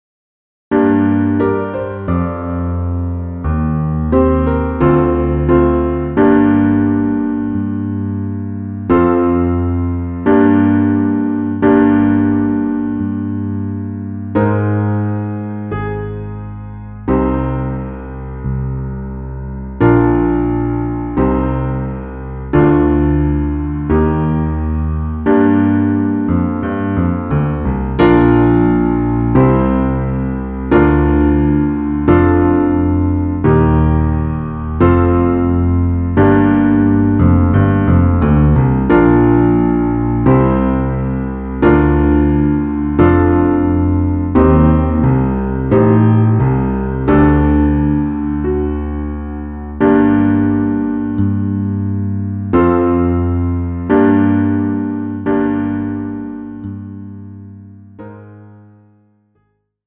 Gattung: 4-Part Ensemble
Besetzung: Ensemble gemischt
Piano/Keyboard optional, inklusive Play-Along CD.